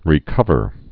(rē-kŭvər)